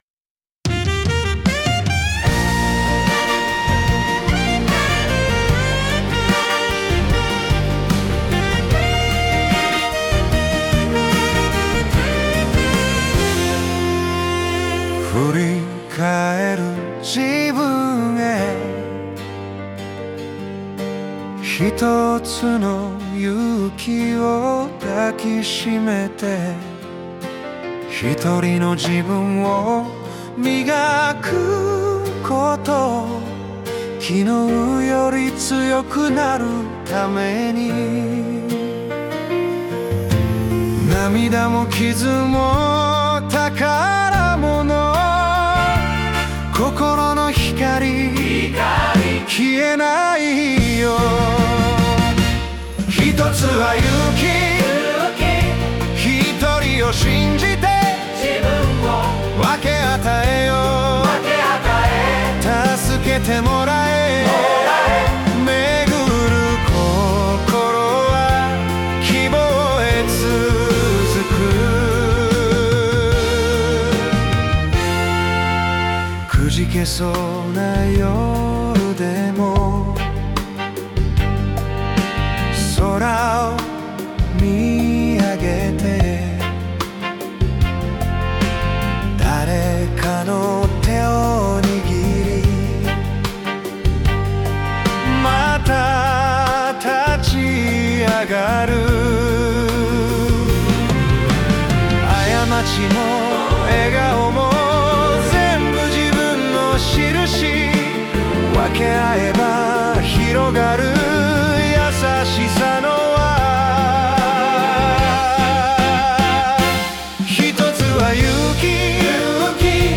イメージ：歌謡曲,哀愁,懐メロ,昭和歌謡,ニューアダルトミュージック,邦楽ポップス
男性ボーカル